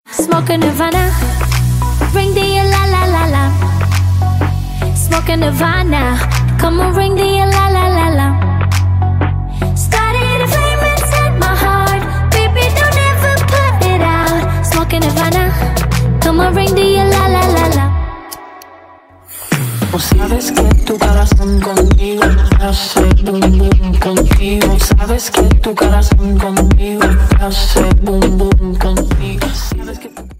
• Качество: 256, Stereo
женский вокал
заводные
dance
красивый женский голос